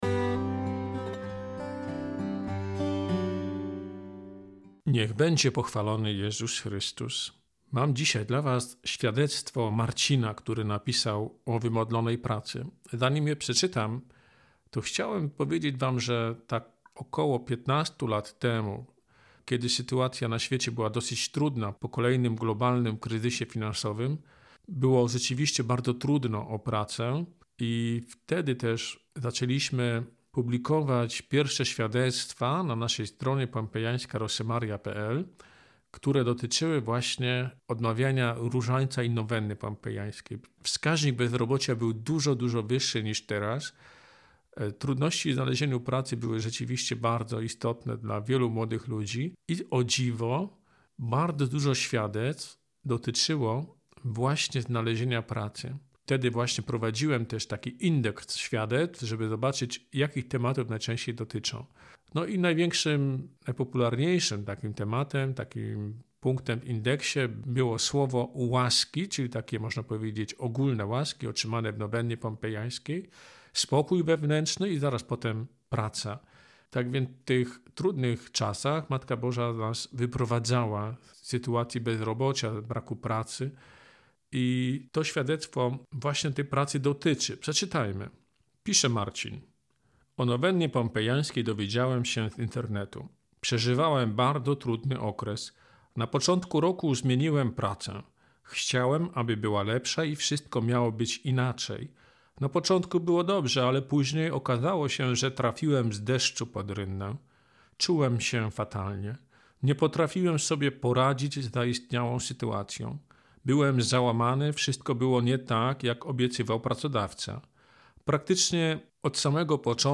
Podcast: Nowenna Pompejańska – świadectwa z komentarzem